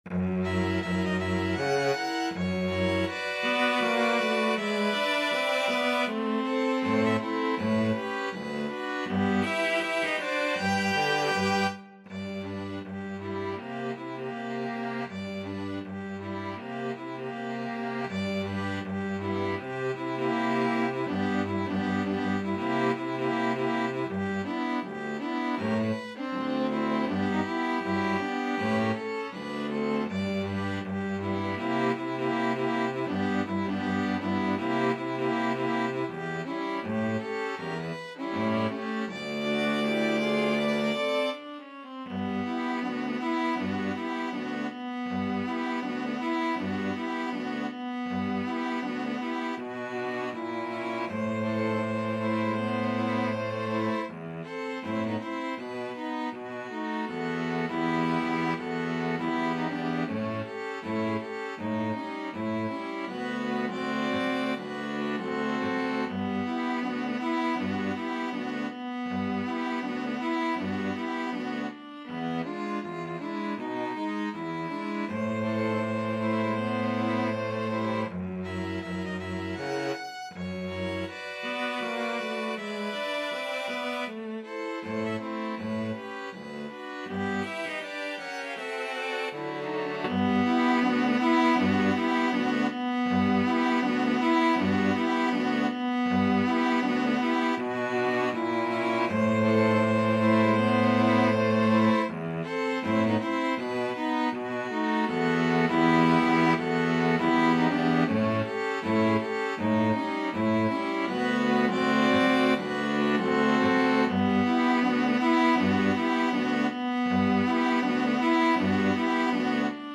String Quartet version
Jazz String Quartet
Violin 1 Violin 2 Viola Cello